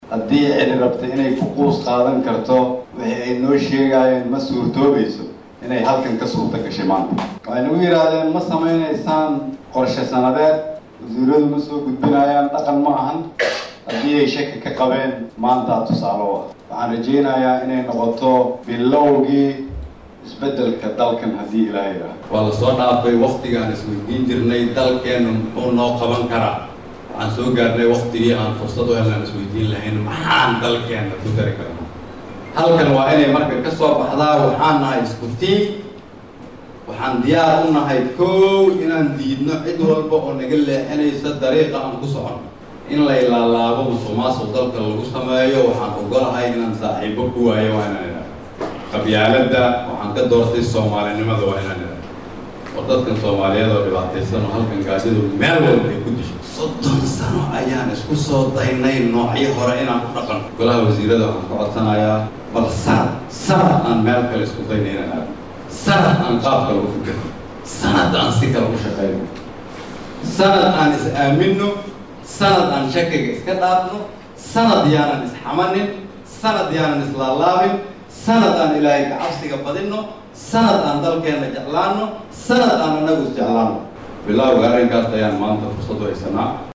Ra’iisul Wasaaraha Soomaaliya Xasan Cali Kheyre oo la hadlay Warbaahinta ayaa waxaa uu sheegay in uu bilowday waqtigii lala xisaabtami lahaa Mas’uuliyiinta dowladda Soomaaliya gaar ahaan Wasiirada.